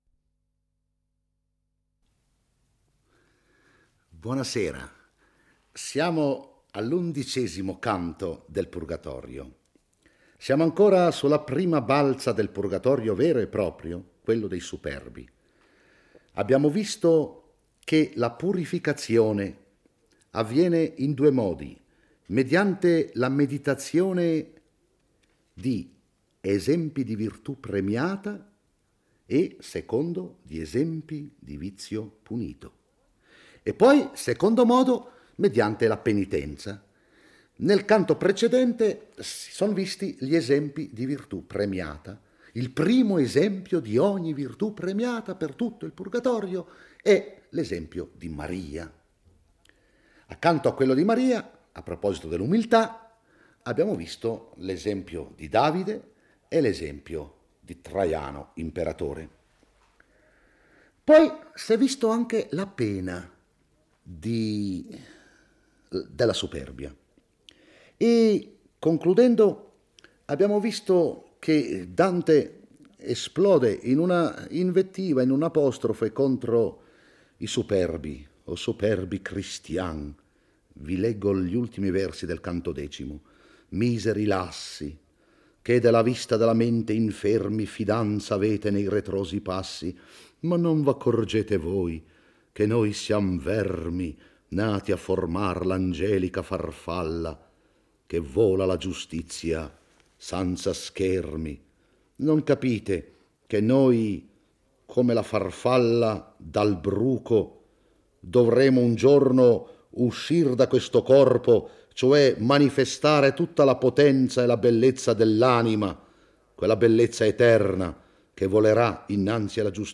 legge e commenta il canto XI del Purgatorio. Schiacciate dal peso dei massi e camminando attorno alla cornice, le anime dei superbi espiano le loro colpe. Recitano il Pater Noster, implorando da Dio per gli uomini la salvezza eterna.